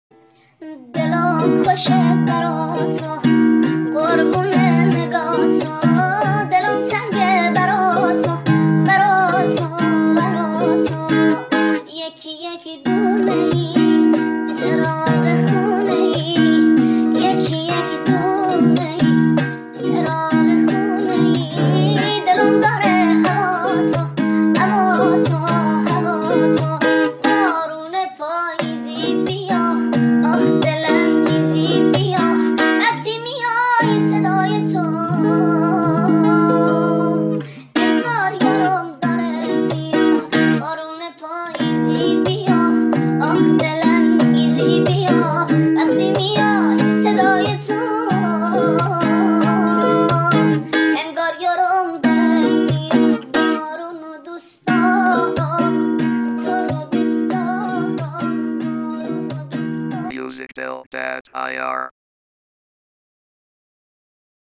ریمیکس شده با صدای نازک